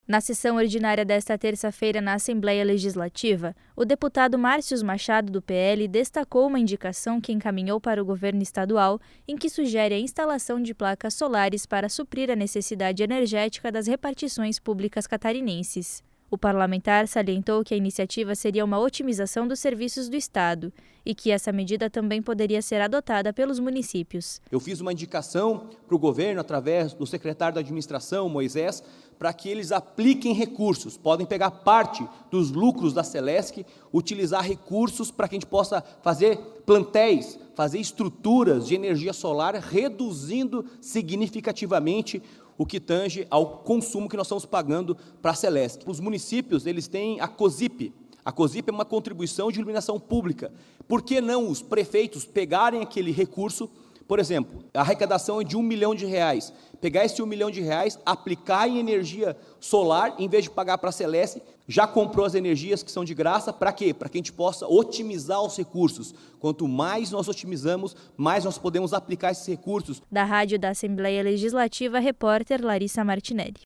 Pronunciamento reivindica placas solares nos prédios públicos do estado